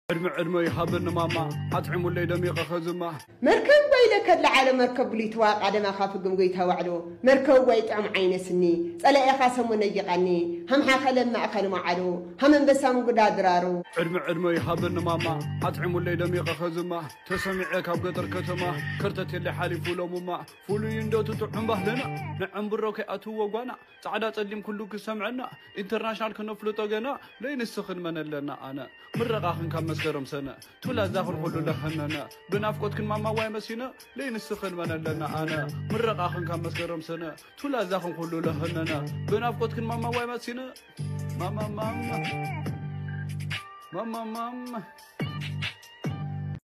old tigrigna music 🎼